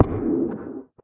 guardian_hit3.ogg